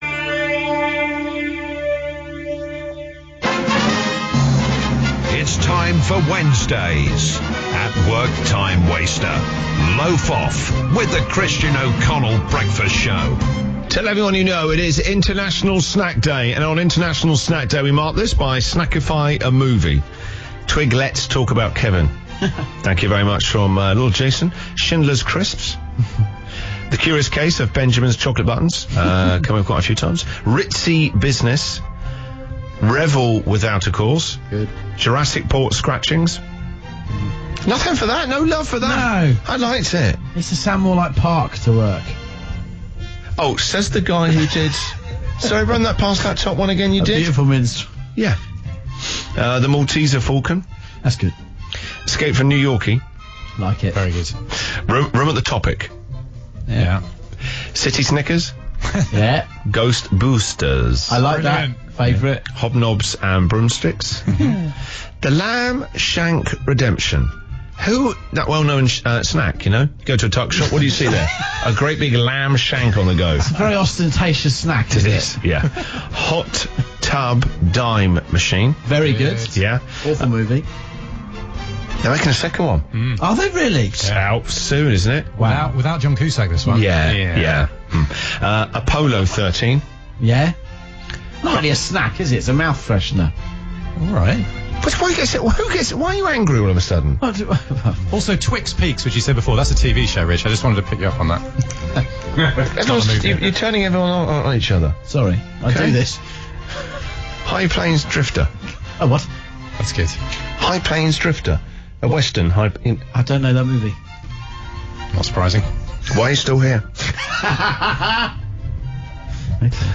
audioBoom advert on Absolute Radio
Weds 4th March, Christian O'Connell Breakfast Show, Absolute Radio.